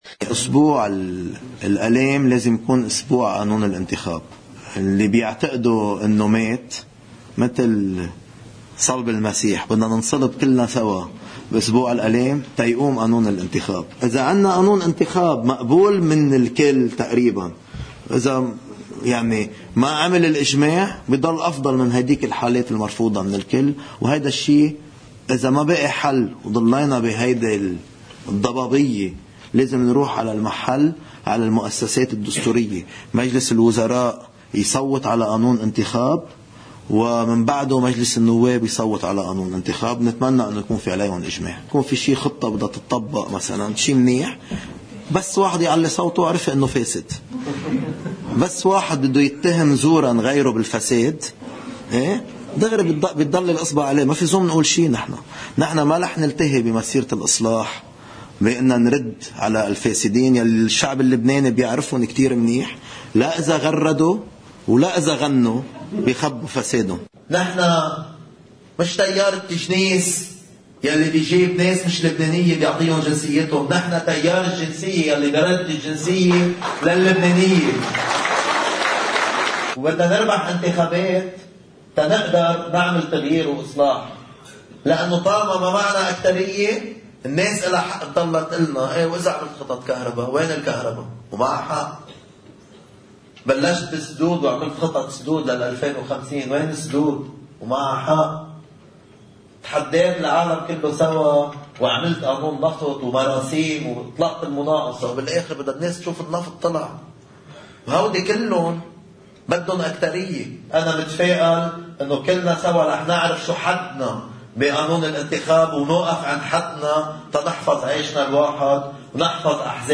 أسبوع الآلام هو اسبوع قانون الإنتخاب، بالفم الملآن قالها وزير الخارجية جبران باسيل، خلال مؤتمر صحفي في سيدني: “كلنا بعدنا طيبين” عبارة ردّ بها على الأحاديث المتعلقة بموت إقتراح التيار الإتنخابي، مؤكداً بانتظار الجواب النهائي من حزب الله قريباً حول بعض النقاط المحددّة.